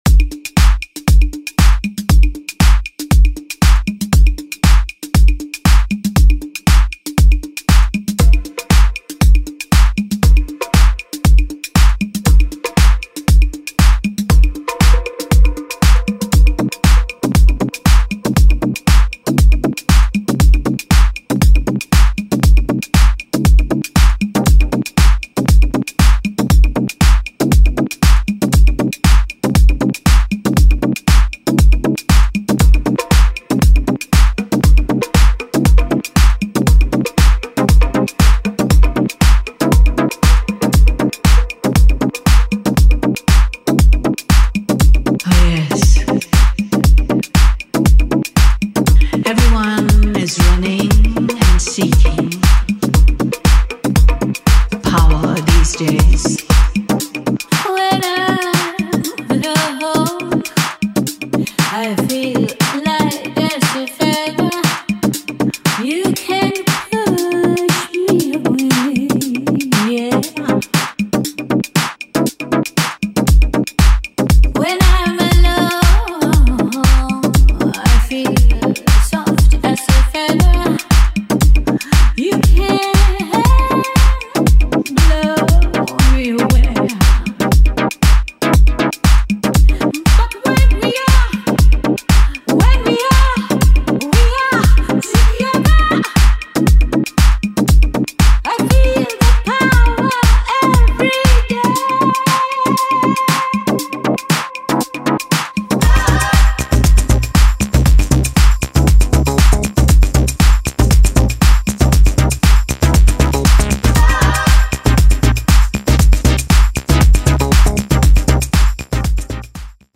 an epic house take with a stomping bassline